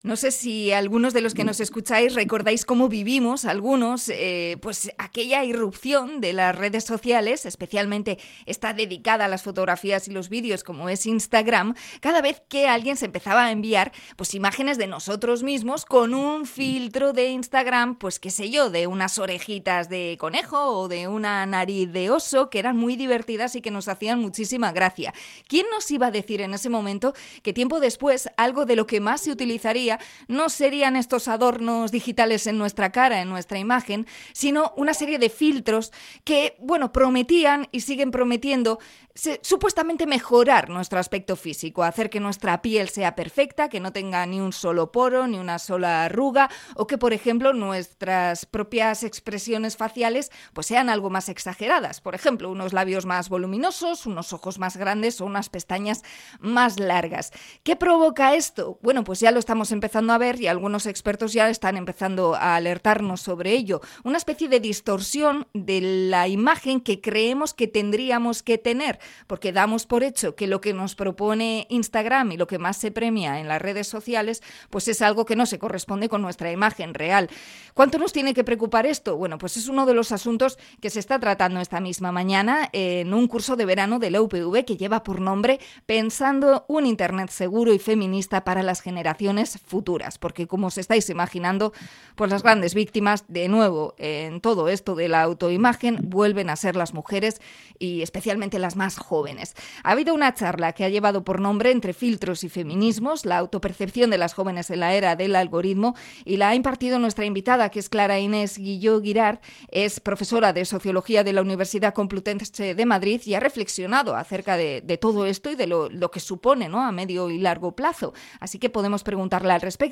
Entrevista a socióloga sobre Isstagram y la imagen de las mujeres